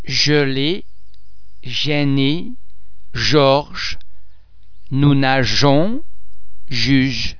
Please be mindful of the fact that all the French sounds are produced with greater facial, throat and other phonatory muscle tension than any English sound.
The French letter [g] before [e] or [ i ] is normally pronounced as the [s] in the English words treasure, pleasure etc.